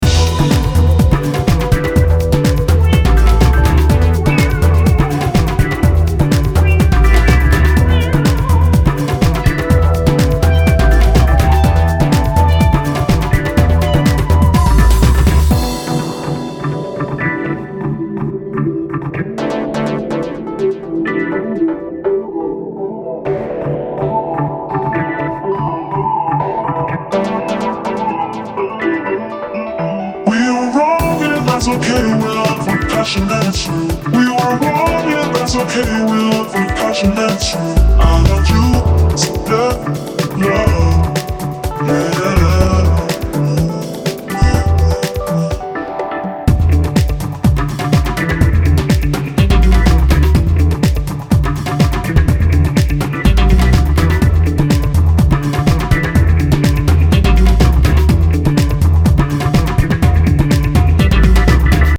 deep singing voice
• Afro House